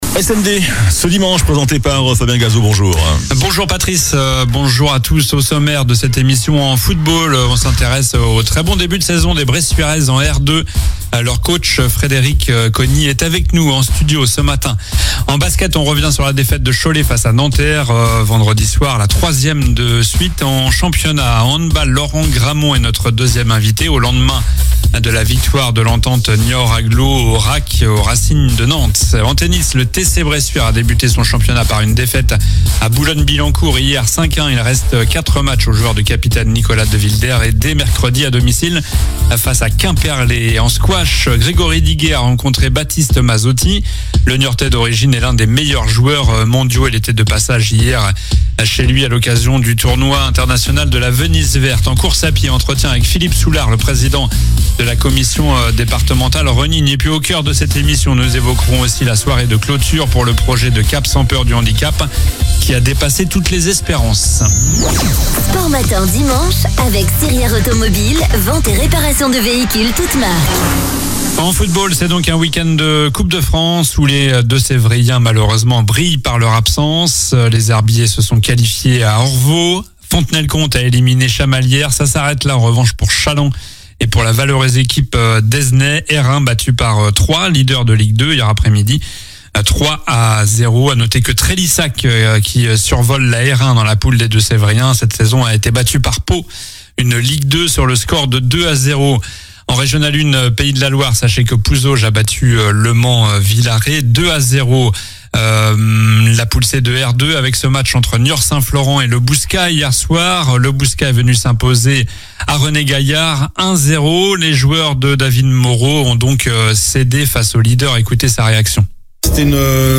est avec nous en studio - En basket, la défaite de Cholet face à Nanterre, la 3e de suite en championnat